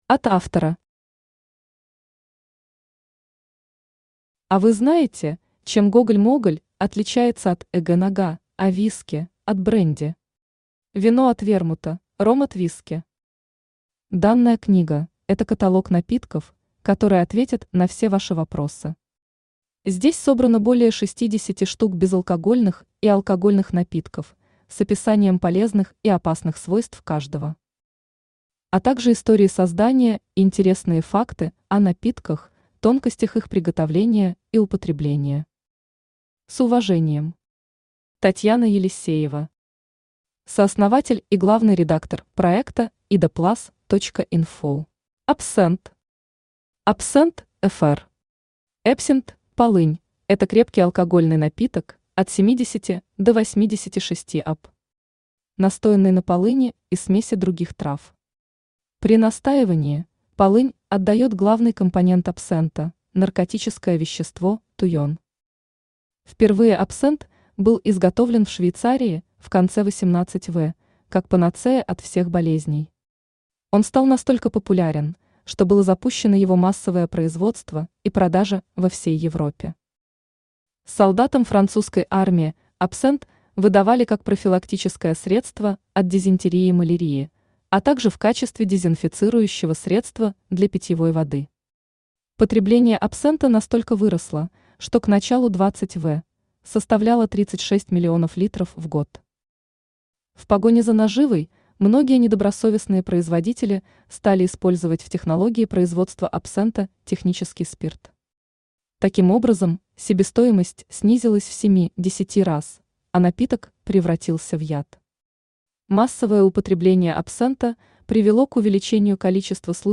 Аудиокнига Каталог напитков. Описание, полезные и опасные свойства | Библиотека аудиокниг
Читает аудиокнигу Авточтец ЛитРес.